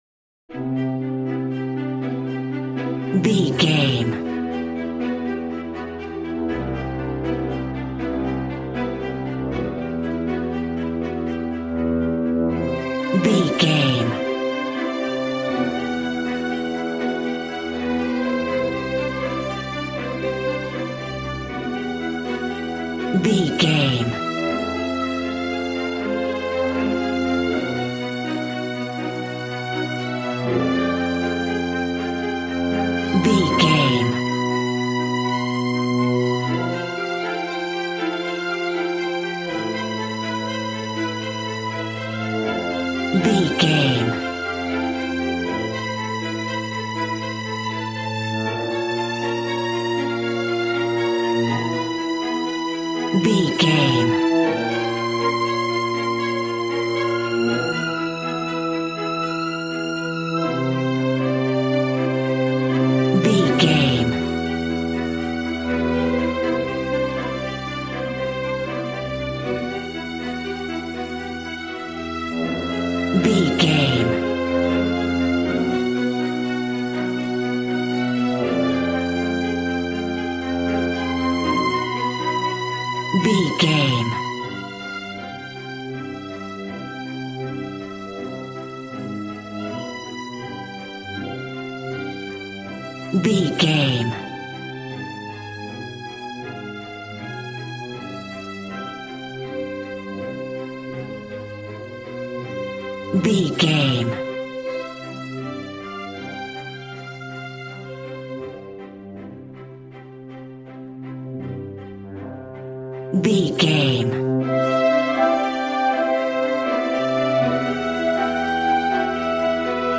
A classical music mood from the orchestra.
Regal and romantic, a classy piece of classical music.
Ionian/Major
regal
cello
violin
strings